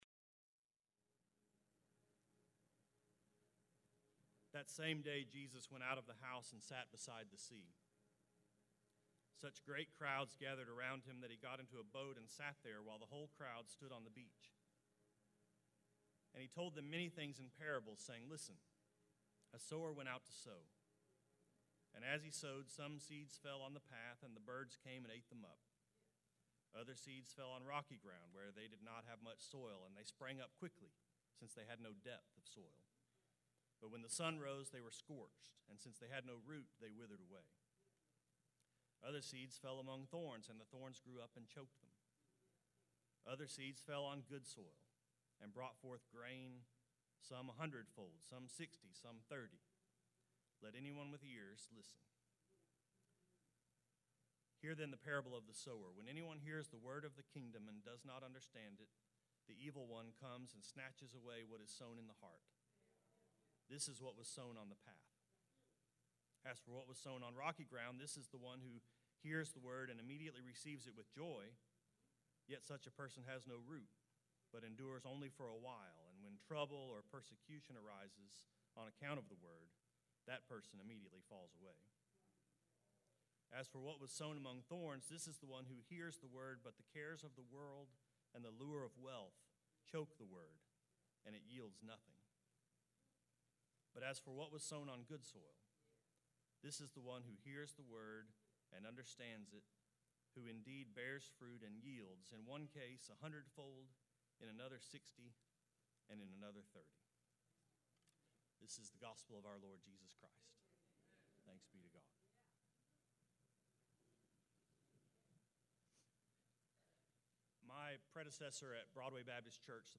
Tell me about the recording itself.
This sermon was preached on July 16, 2017 at Rising Star Baptist Church in Fort Worth, Texas.